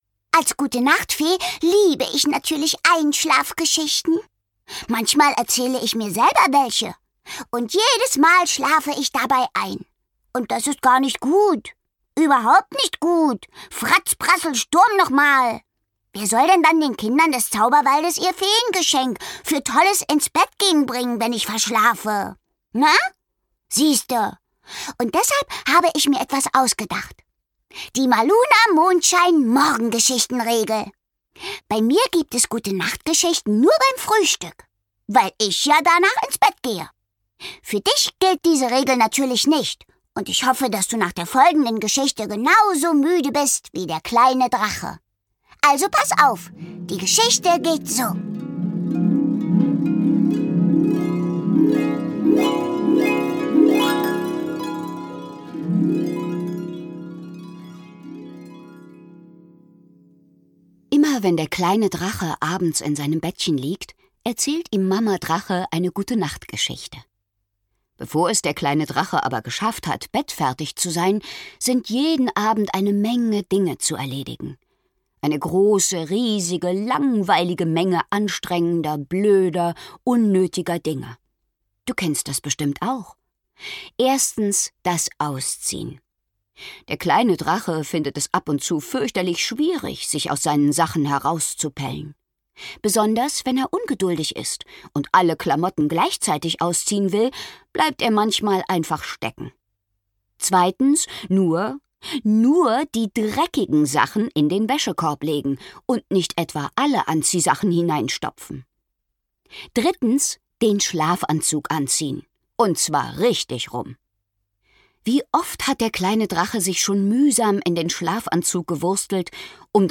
Mitarbeit Sprecher: Cathlen Gawlich
Schlagworte Feen • Feen; Kinder-/Jugendliteratur • Geschichten • Hörbuch; Lesung für Kinder/Jugendliche • Zauberwald